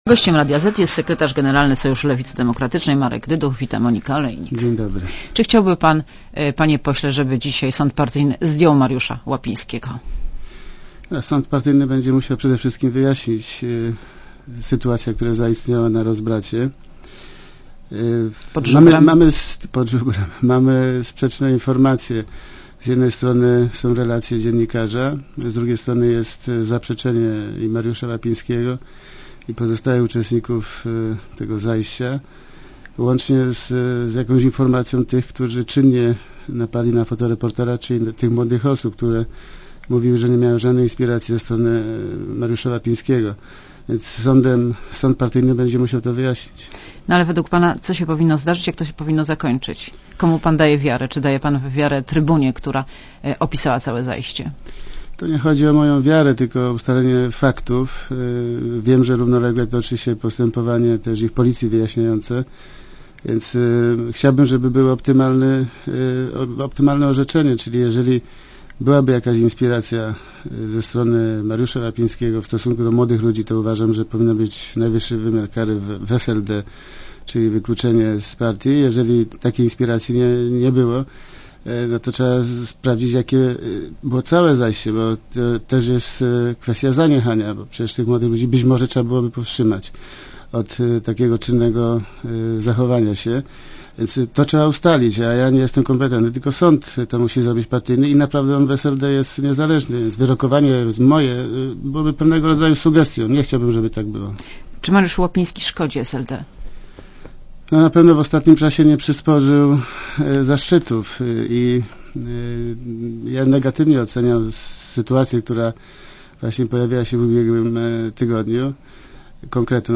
© (RadioZet) Posłuchaj wywiadu (2,5 MB) Czy chciałby pan, panie pośle, żeby dzisiaj sąd partyjny zdjął Mariusza Łapińskiego ?